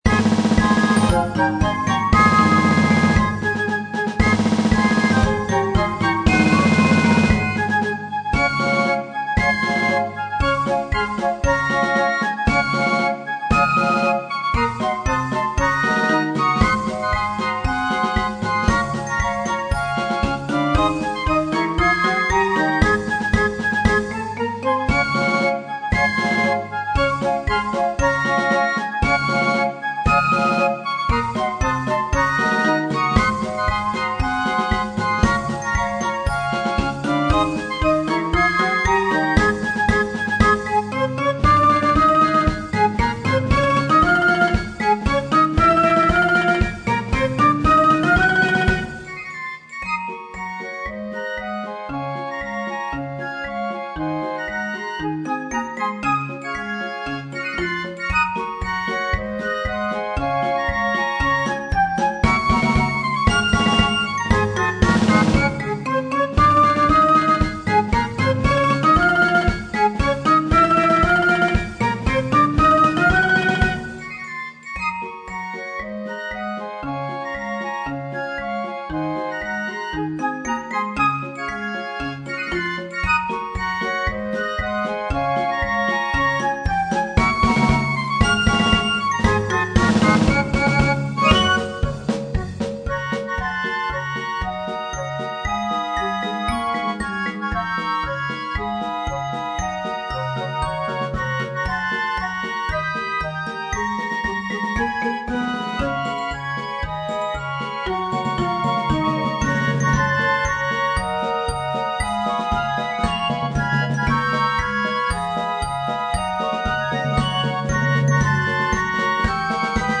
Klangbeispiel Ces/Fes-Besetzung